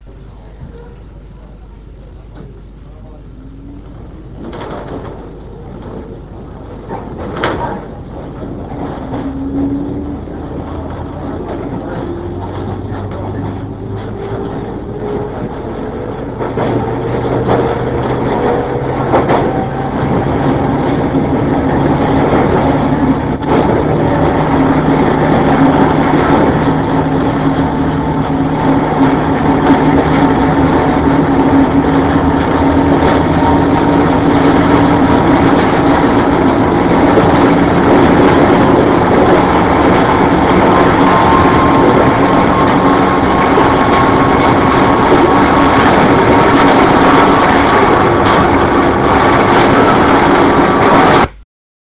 １０３系の音
１０３系加速音 原木中山→行徳（妙典） 106kb RealAudio形式
途中から雑音が入って聞きづらいと思いますが、加速する音が聞こえます。